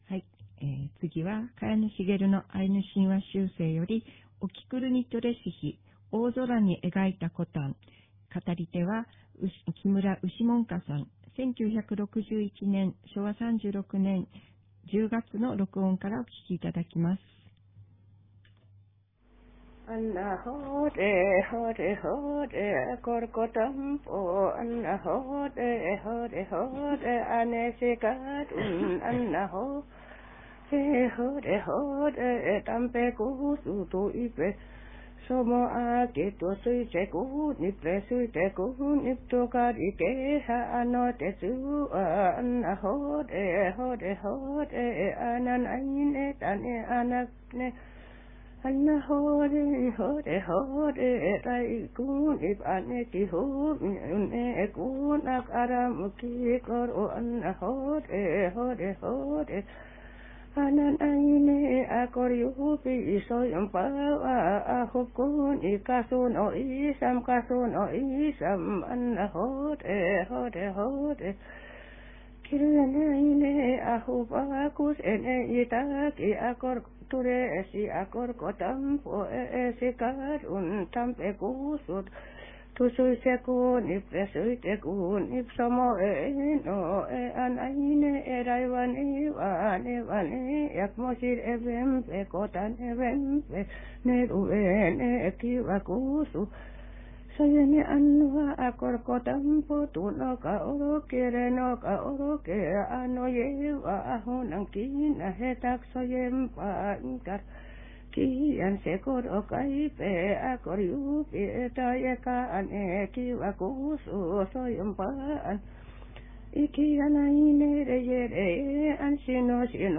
語り